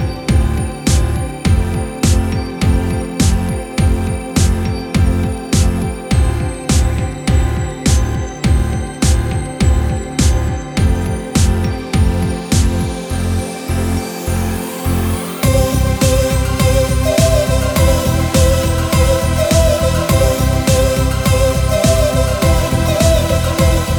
No Worded Backing Vocals Pop (2010s) 4:45 Buy £1.50